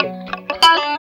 28 GUIT 3 -L.wav